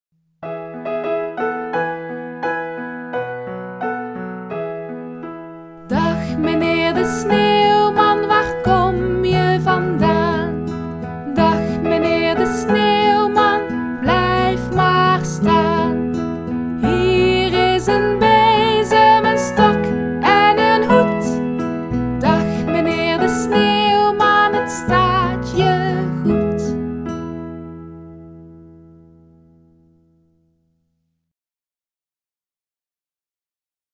Genre: Blues.
dagmeneerdesneeuwman_metzang.mp3